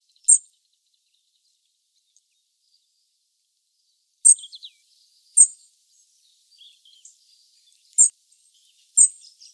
Erithacus rubecula - Robin - Pettirosso